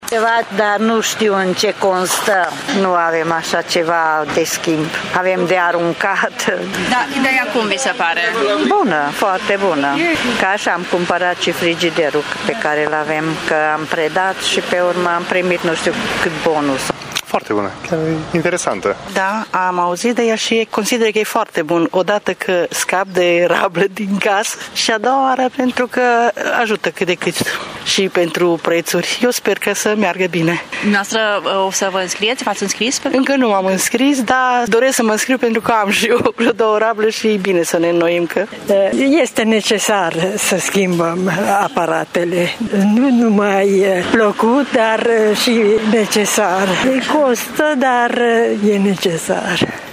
Mureșenii spun că vor profita de acest program deoarece aparatura lor este deja uzată: